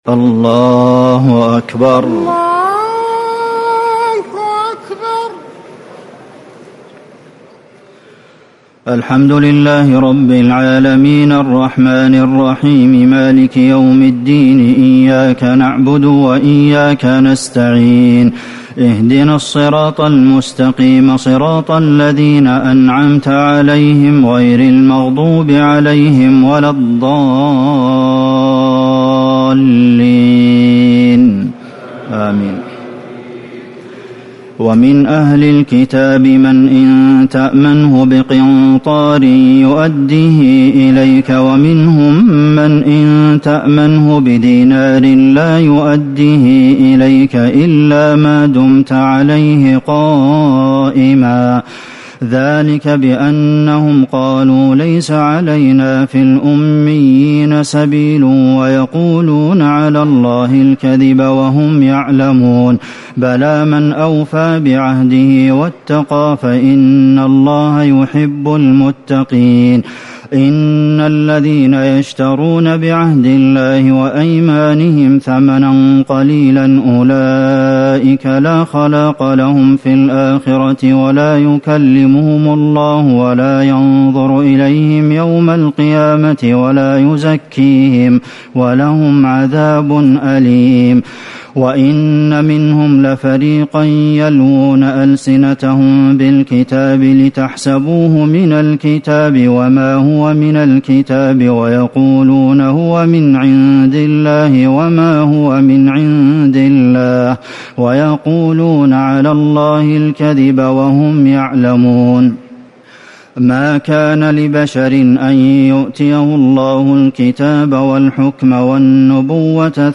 ليلة ٣ رمضان ١٤٤٠ من سورة ال عمران ٧٥-١٥٢ > تراويح الحرم النبوي عام 1440 🕌 > التراويح - تلاوات الحرمين